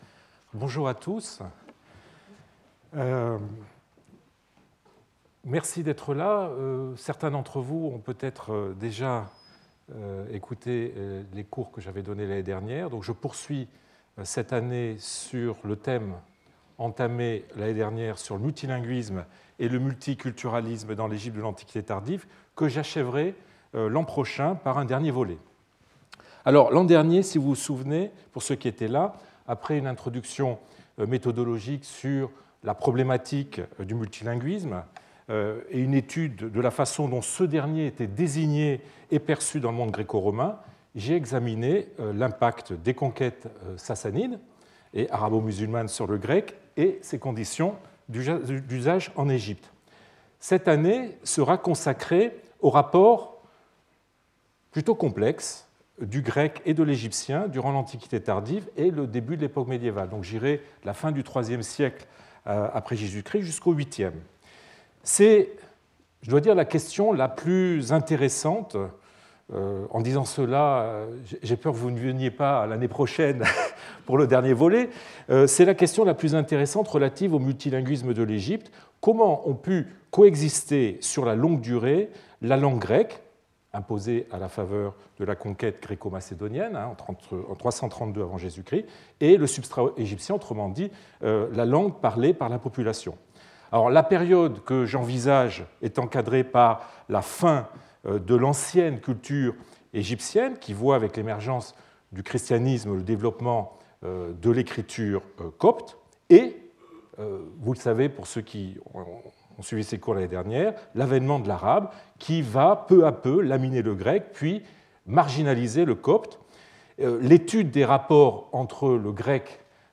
Sauter le player vidéo Youtube Écouter l'audio Télécharger l'audio Lecture audio Le premier cours esquisse, en guise d’introduction, la situation antérieure à l’émergence du copte et dessine le contexte sociolinguistique dans lequel ce dernier prendra place.